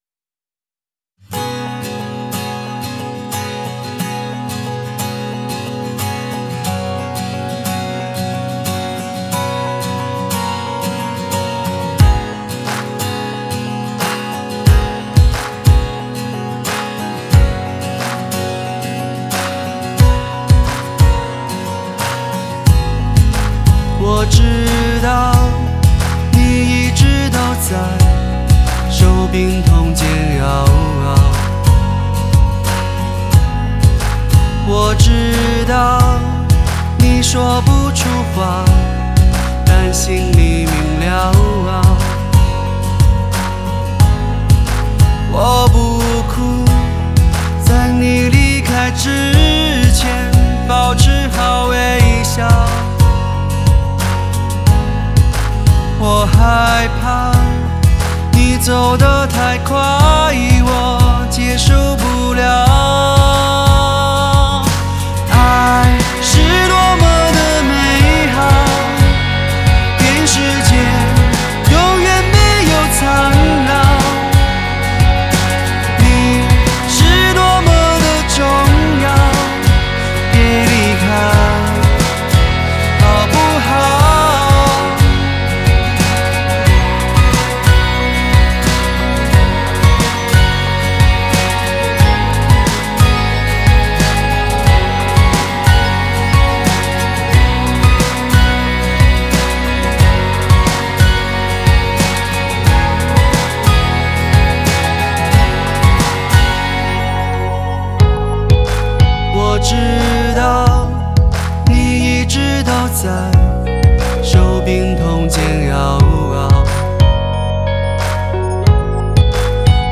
风格很有活力和创新
偏向与英伦摇滚